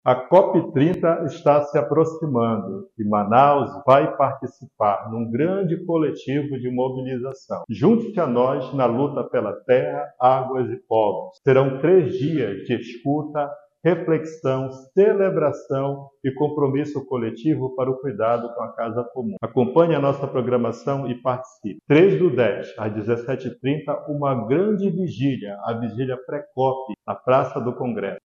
O Bispo Auxiliar de Manaus, Dom Hudson Ribeiro, reforçou a importância desses momentos de união, destacando a vigília e as atividades como uma oportunidade para fortalecer a voz do povo amazônico no cenário global.
SONORA-1-DOM-HUDSON-.mp3